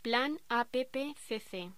Locución: A.P.P.C.C.
voz